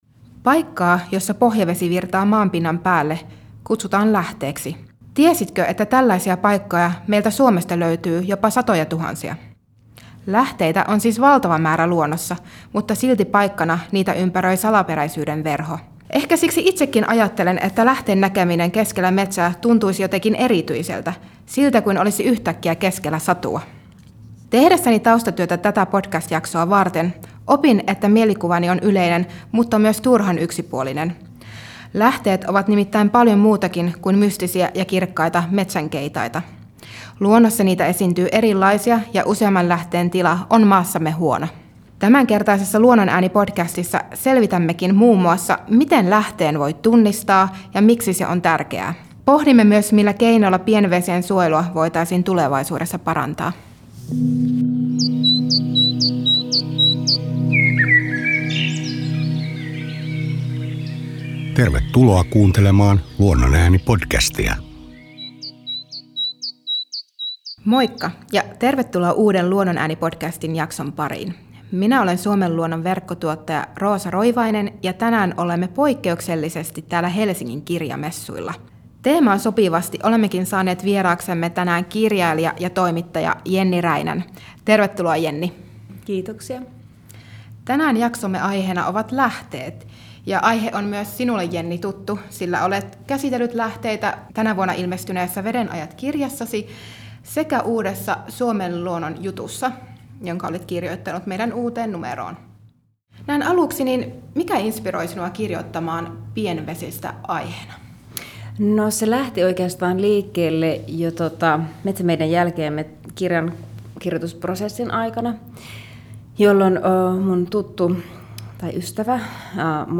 Studioon saapuu kirjailija ja toimittaja